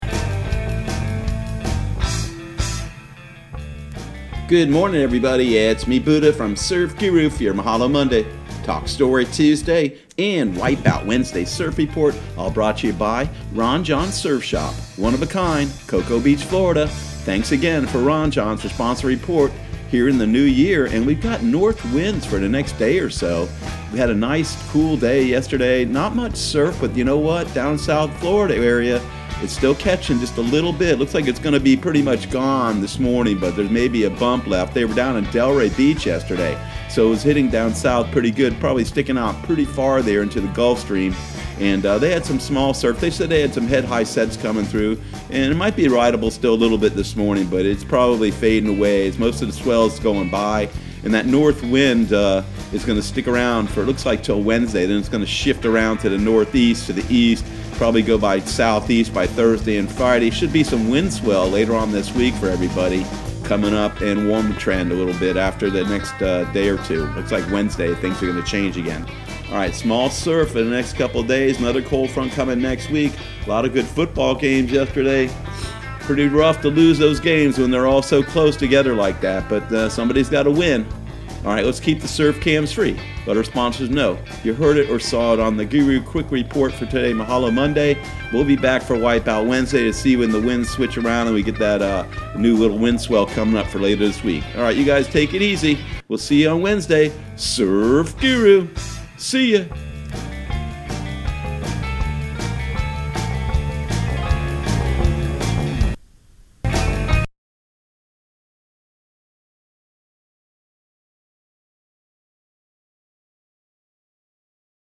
Surf Guru Surf Report and Forecast 01/06/2020 Audio surf report and surf forecast on January 06 for Central Florida and the Southeast.